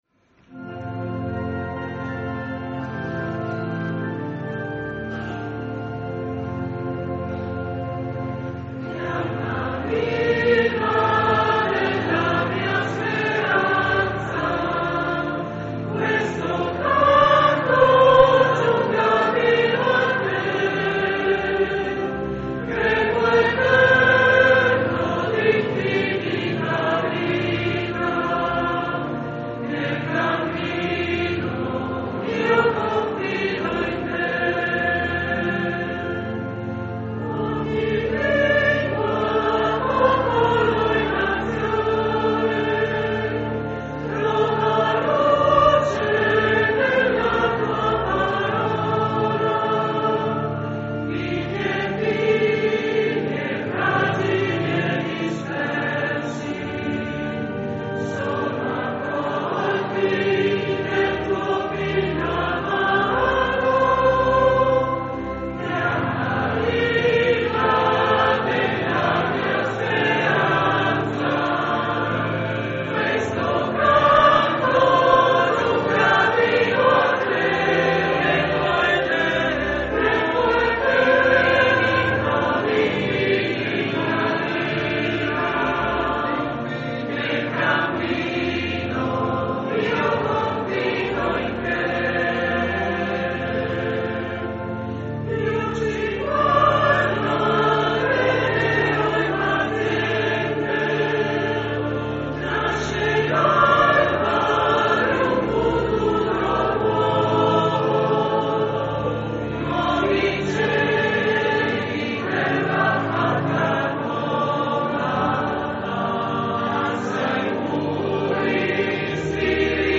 accompagnata dal Coro Interparrocchiale
...e dopo la benedizione il canto di chiusura...